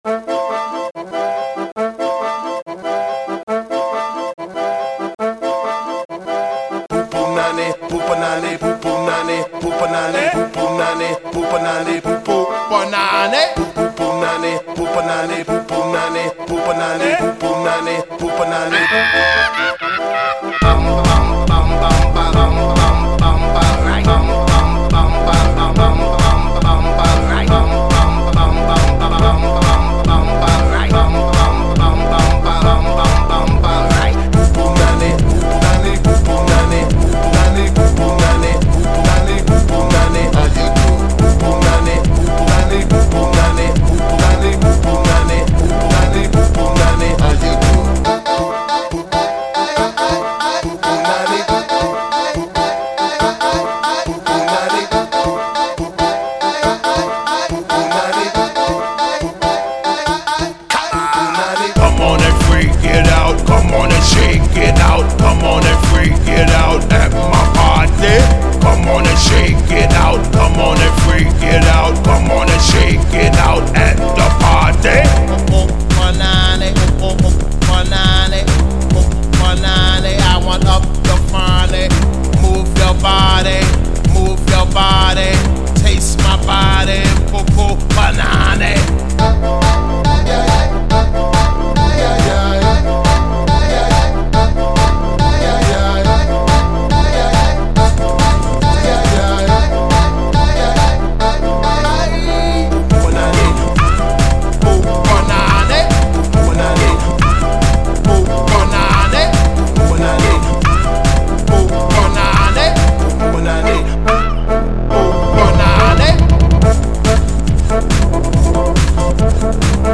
Русско-африканский легкий house